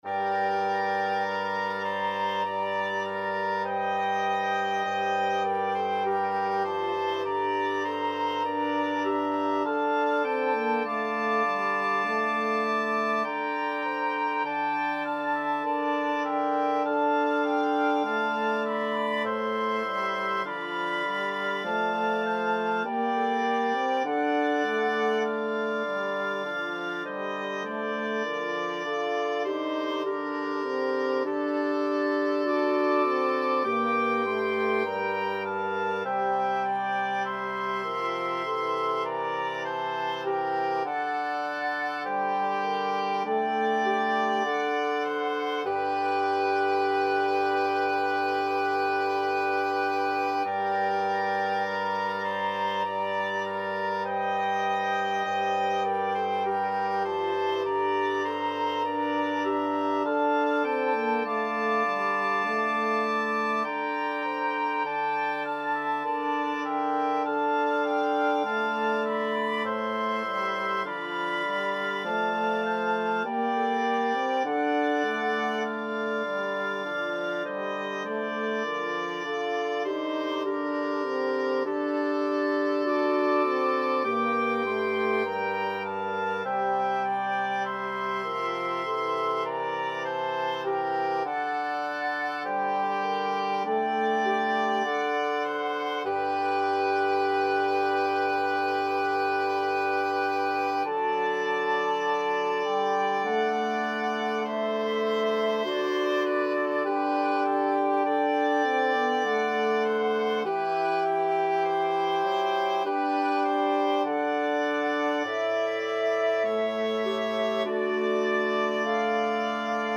Free Sheet music for Wind Quintet
F major (Sounding Pitch) (View more F major Music for Wind Quintet )
2/2 (View more 2/2 Music)
Wind Quintet  (View more Intermediate Wind Quintet Music)
Classical (View more Classical Wind Quintet Music)